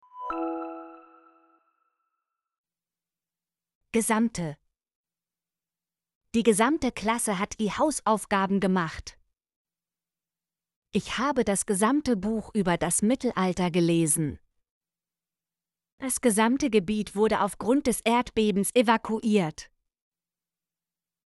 gesamte - Example Sentences & Pronunciation, German Frequency List